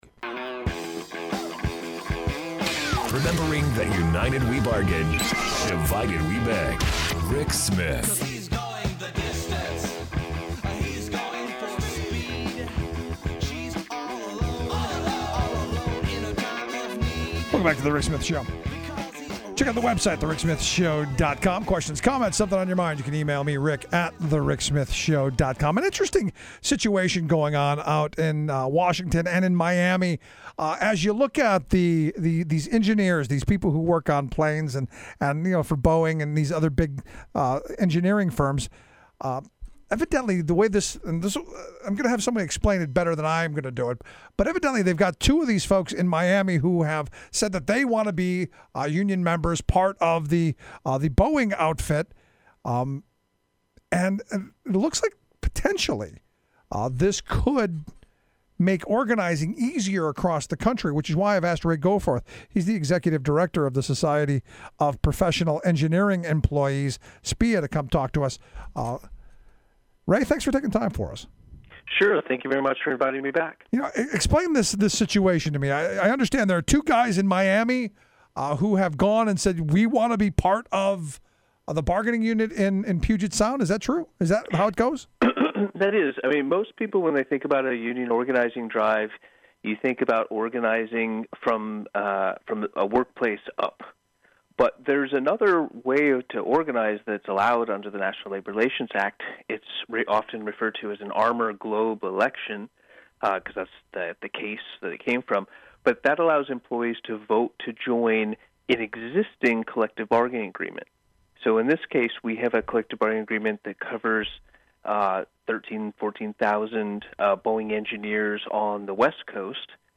Radio intervew